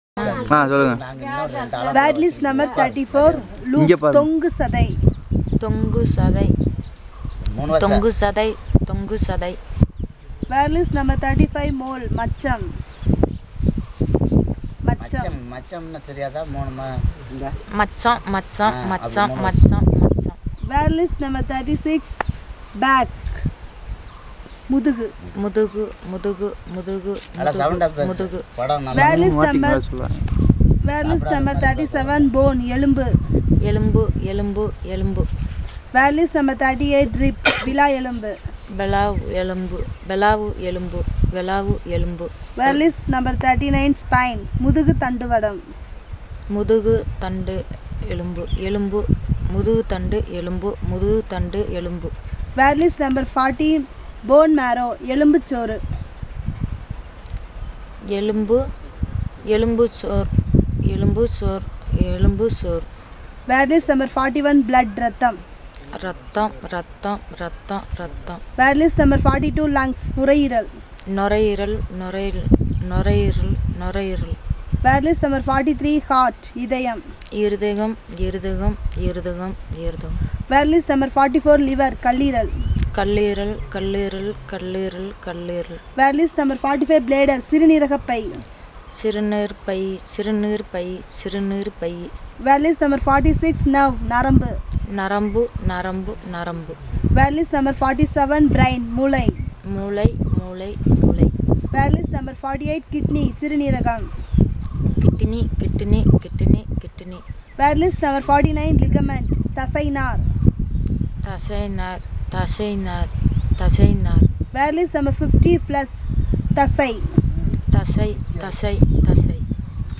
NotesThis is an elicitation of words about human body parts, using the SPPEL Language Documentation Handbook.